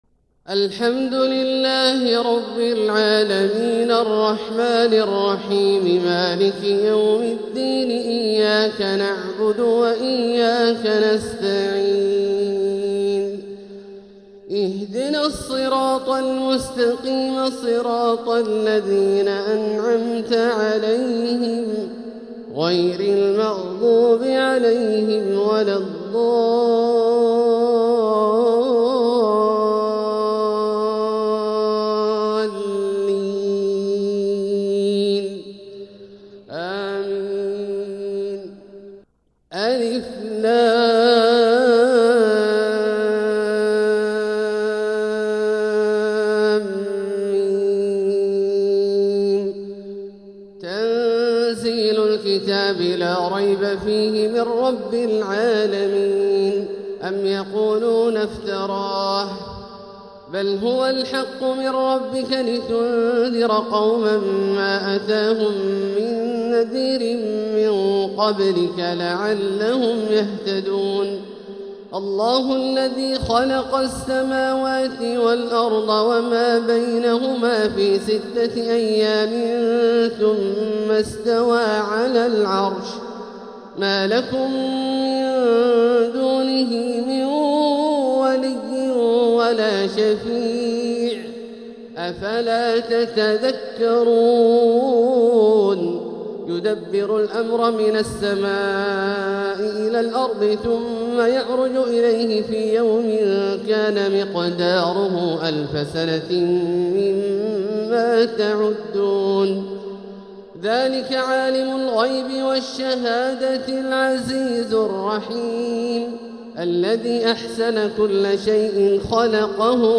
تلاوة لسورتي السجدة والإنسان | فجر الجمعة ٢٠ شوال ١٤٤٦ هـ > ١٤٤٦ هـ > الفروض - تلاوات عبدالله الجهني